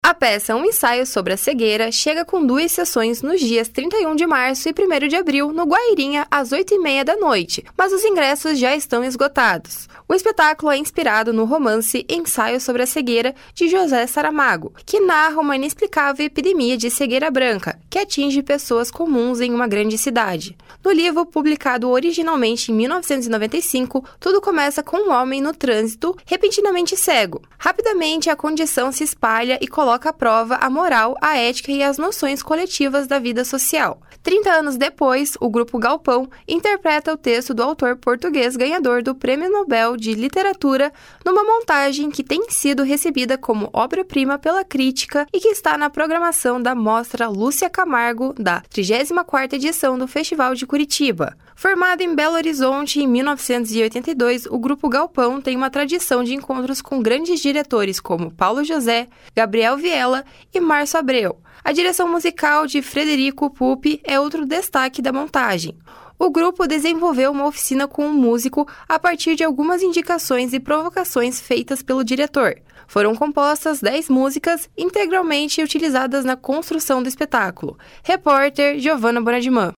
(Repórter: